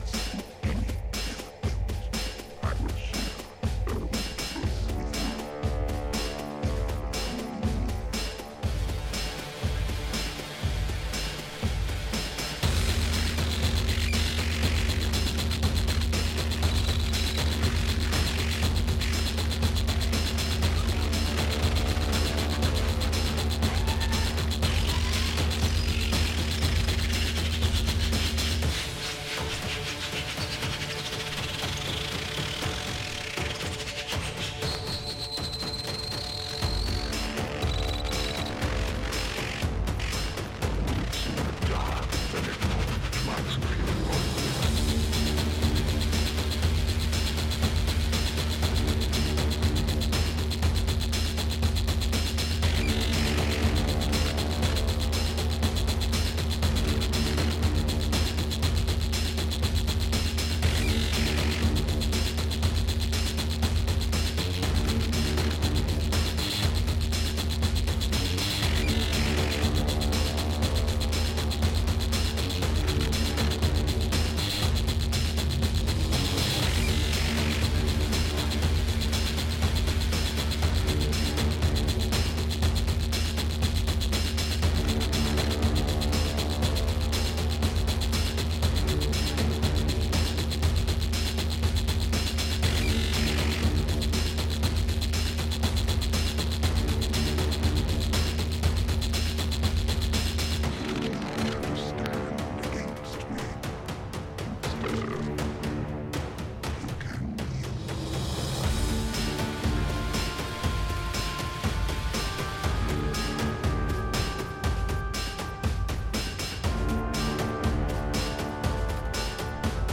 EBM/Industrial, Techno